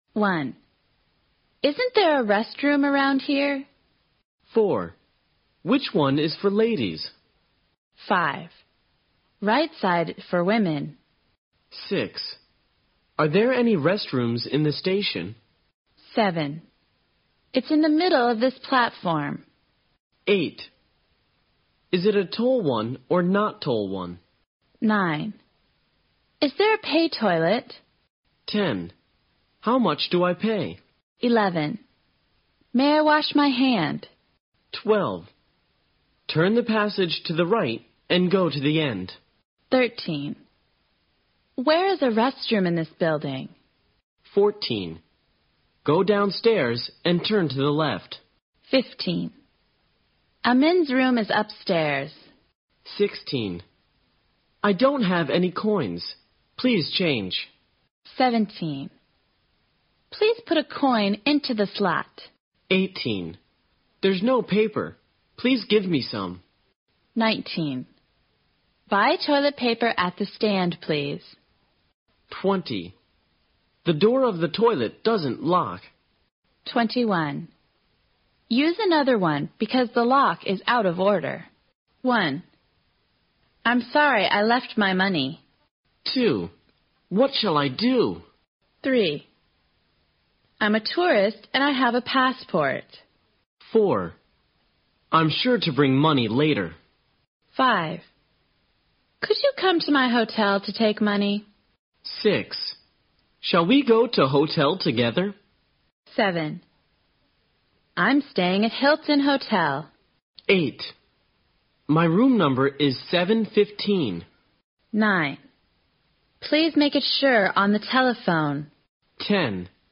在线英语听力室随身应急英语会话 第14期:在城里的紧急情况(2)的听力文件下载, 《随身应急英语会话》包含中英字幕以及地道的英语发音音频文件，是学习英语口语，练习英语听力，培养提高英语口语对话交际能力的好材料。